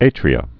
(ātrē-ə)